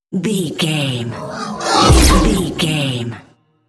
Creature sci fi shot appear
Sound Effects
Atonal
tension
ominous
eerie
whoosh